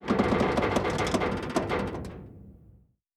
pgs/Assets/Audio/Sci-Fi Sounds/MISC/Metal Foley Creak 3.wav at master
Metal Foley Creak 3.wav